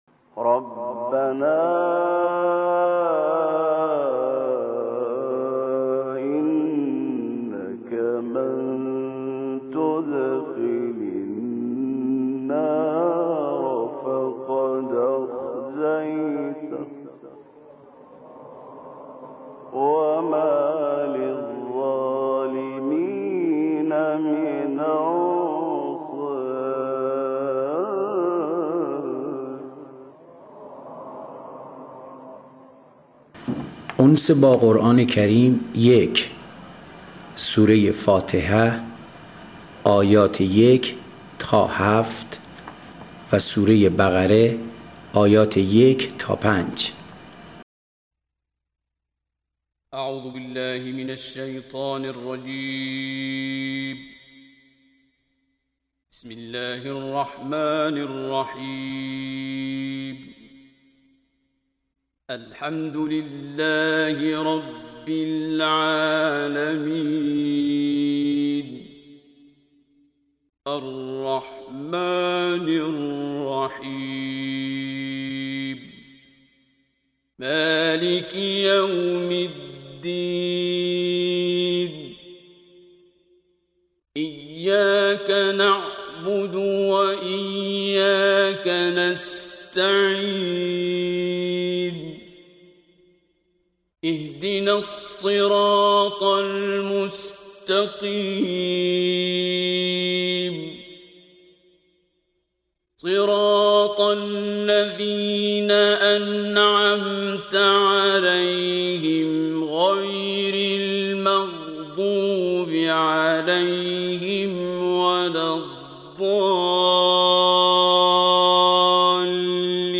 قرائت آیات سوره حمد + آیات 1 الی 5 سوره بقره